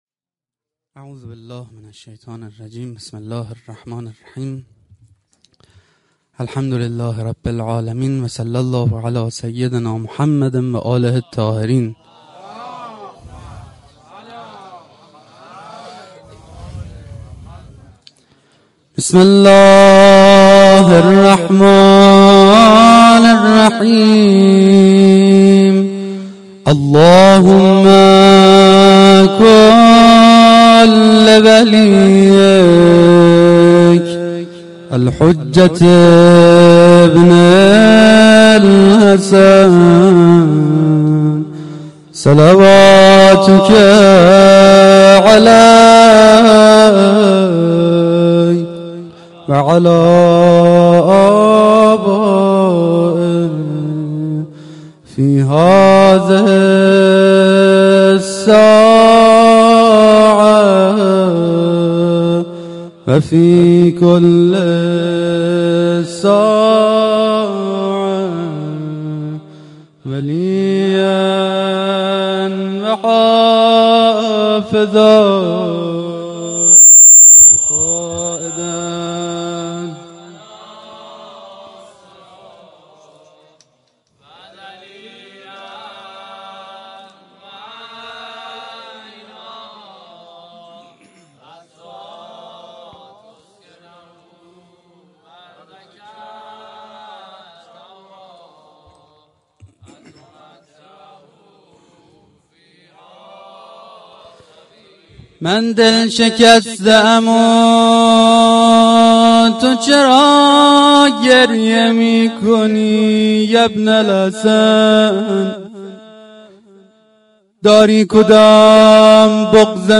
جشن میلاد سرداران کربلا1396 :: هیئت محبین الرضا (علیه السلام)
میلاد-سرداران-کربلا-96-سخنرانی.mp3